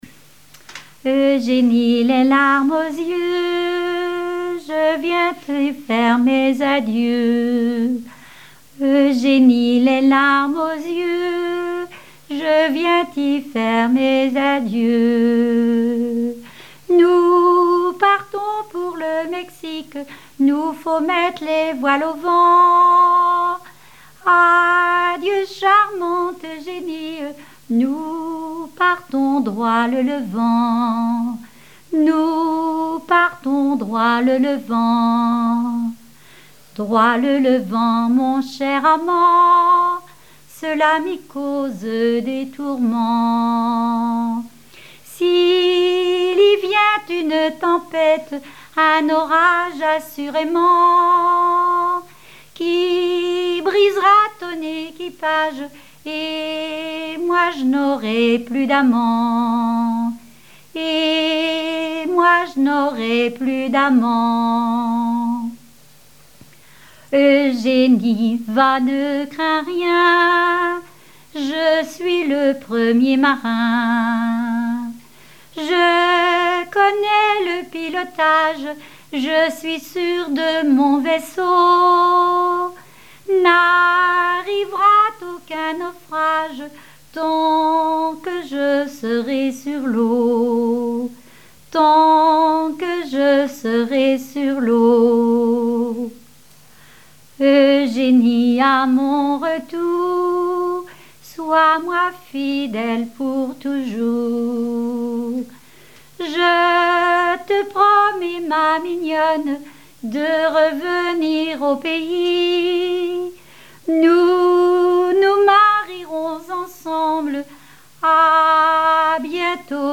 Genre strophique
chansons populaires et traditionnelles
Pièce musicale inédite